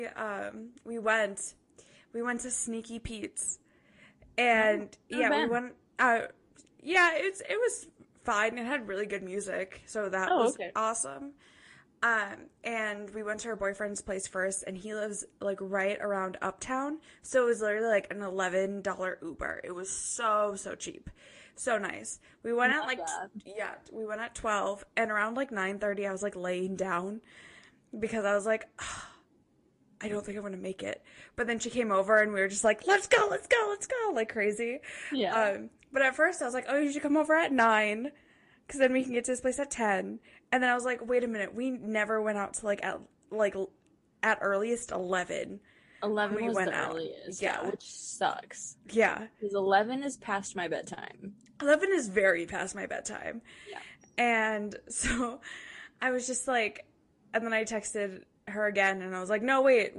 They can’t be together in person but that doesn’t stop the random thoughts from colliding in their chaotic conversations.